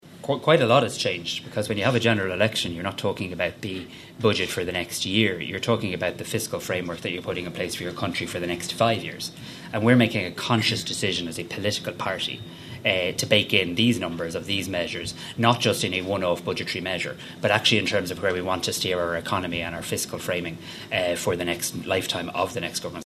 Taoiseach Simon Harris defended the decision to make this promise now – having not implemented it in the recent budget.